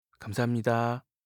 알림음 8_감사합니다4-남자.mp3